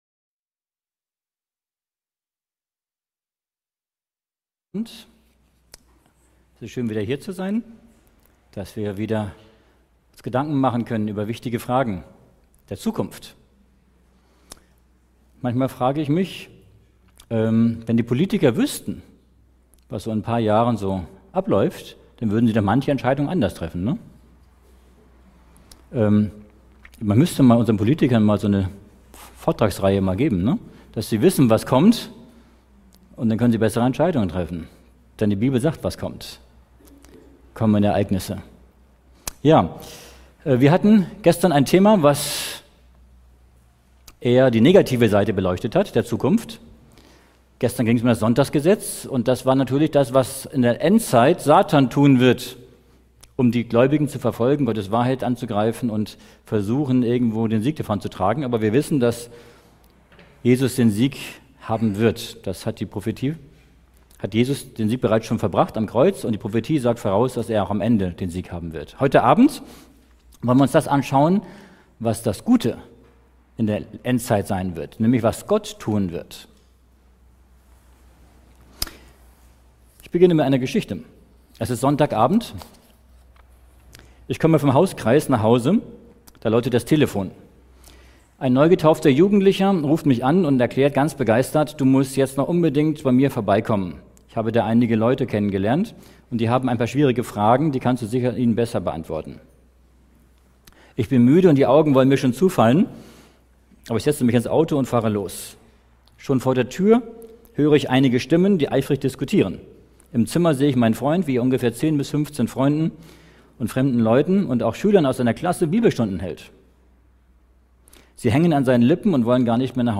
In einem fesselnden Vortrag wird beleuchtet, wie der Spätregen des Heiligen Geistes Wunder wirkt und die Gläubigen auf die baldige Wiederkunft Jesu vorbereitet.